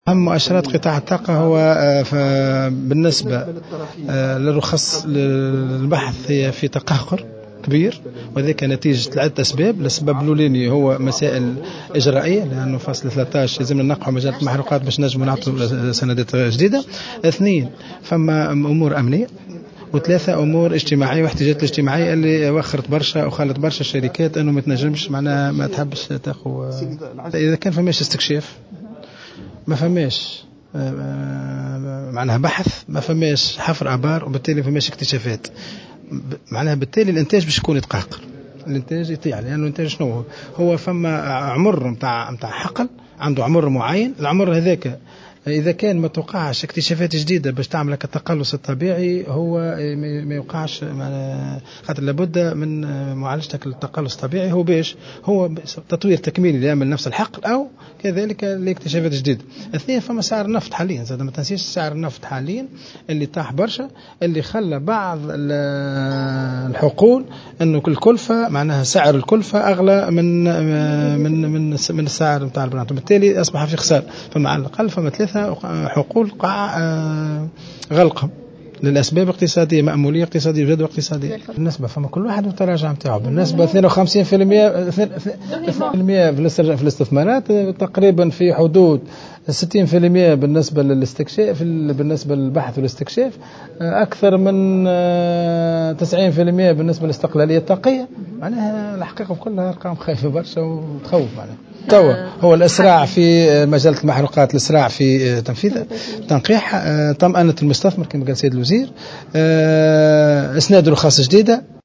وأوضح خلال ندوة وطنية انتظمت اليوم حول الطاقة بحضور عدد من الخبراء والمختصين في هذا المجال أن هذا التراجع يعود إلى عدّة أسباب منها الوضع الأمني و الاحتجاجات الاجتماعية التي تشهدها بعض المؤسسات، مشيرا إلى أنه وقع غلق 3 حقول لأسباب اقتصادية.